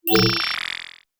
Add sound effects for buff management and notifications
UI_SFX_Pack_61_10.wav